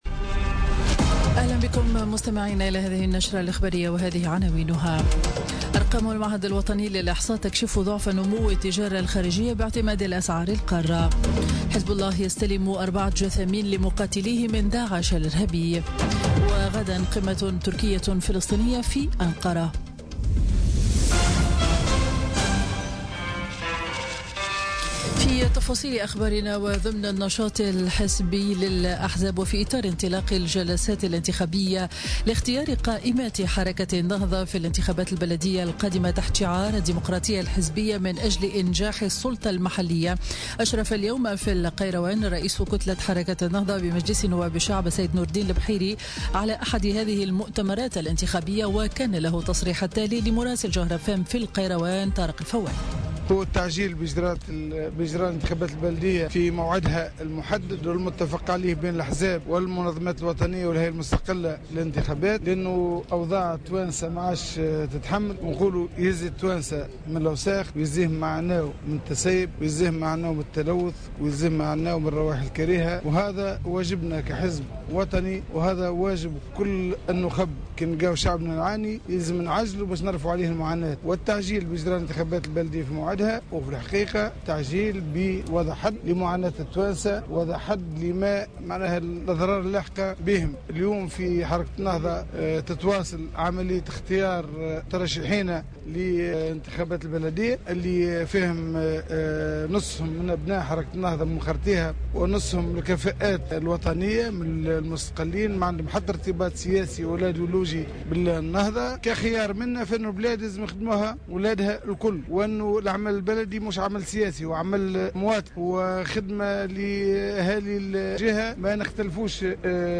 نشرة أخبار منتصف النهار ليوم الأحد 27 أوت 2017